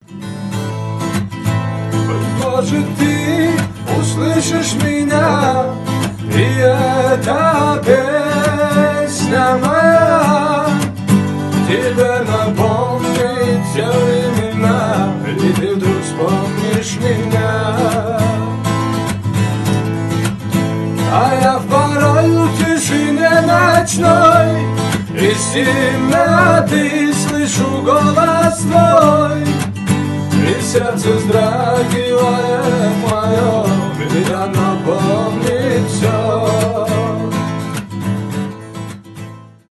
live , акустика , гитара , душевные